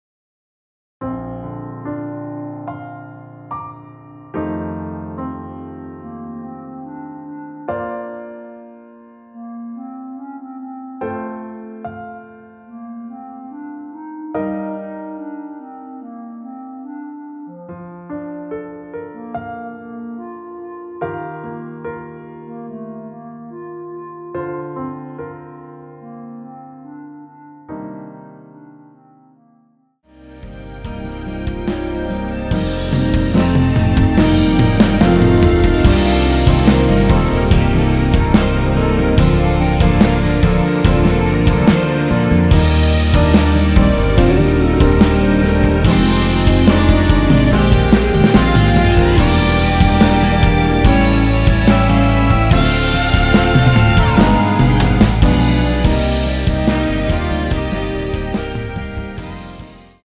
원키에서 (-2)내린 멜로디 포함된 MR 입니다.
노래가 바로 시작 하는 곡이라 전주 만들어 놓았습니다.
6초쯤에 노래 시작 됩니다.(미리듣기 참조)
Bb
앞부분30초, 뒷부분30초씩 편집해서 올려 드리고 있습니다.